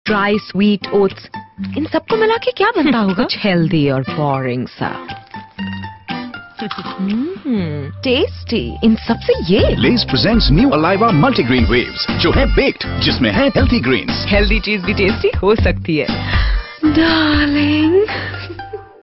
File Category : Free mobile ringtones > > Tv ringtones
File Type : Tv confectionery ads